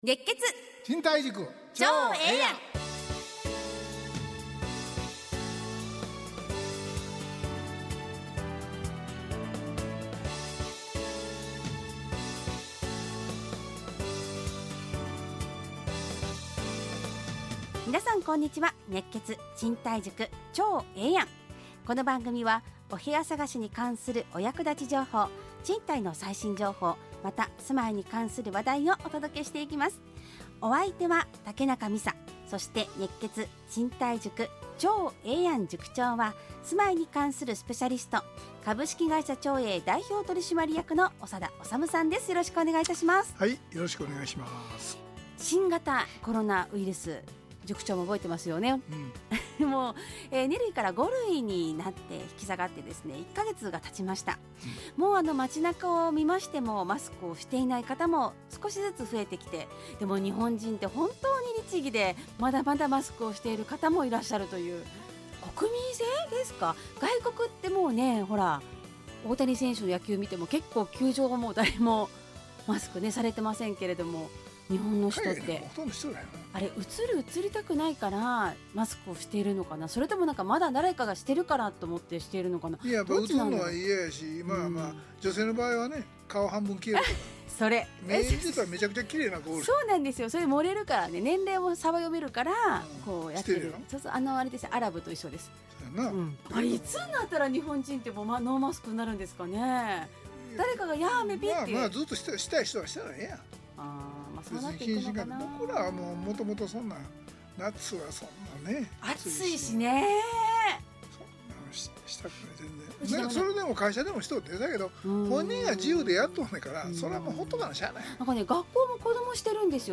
ラジオ放送 2023-06-16 熱血！